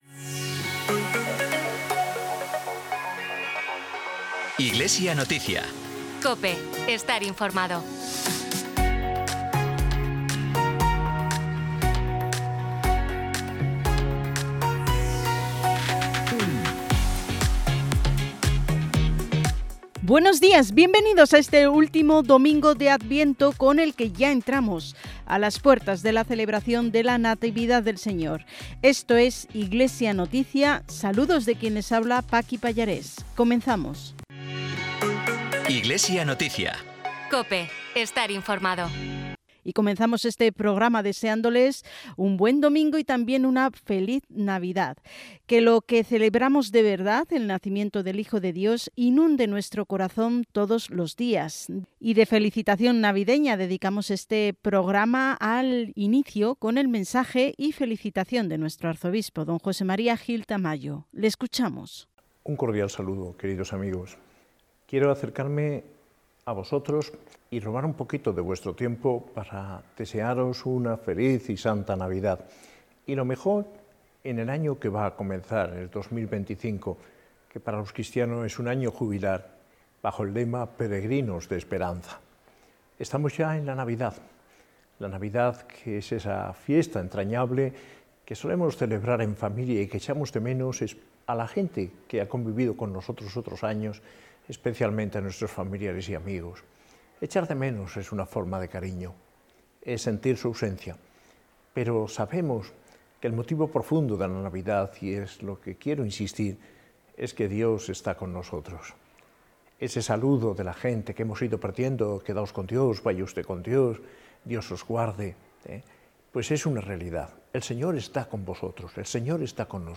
Programa informativo emitido en COPE Granada y COPE Motril el 22 de diciembre de 2024.
En vísperas de la celebración de Navidad, el arzobispo Mons. José María Gil Tamayo dirige un mensaje a todo el mundo invitando a la contemplación del Misterio del Nacimiento del Hijo de Dios y una felicitación navideña.